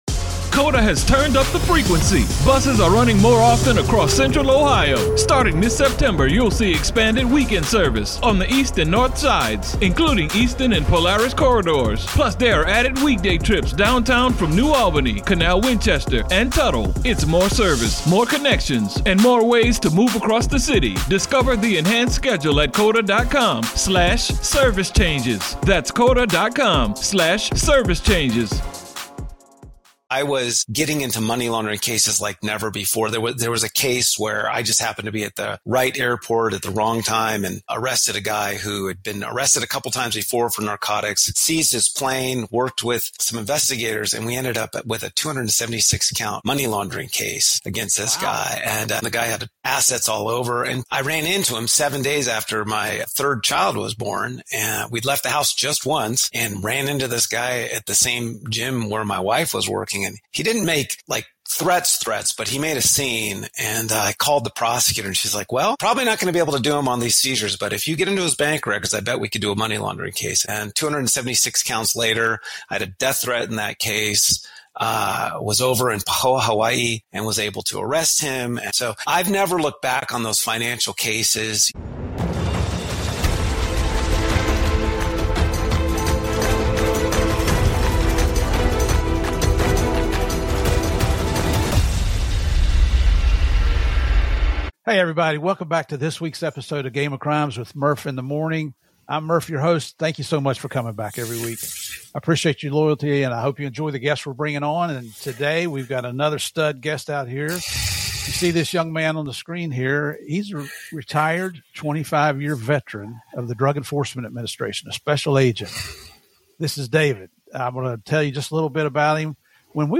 Packed with humor, danger, and powerful lessons, this conversation shines a light on the hidden world of cartels, financial crimes, and the agents who risk it all to protect us.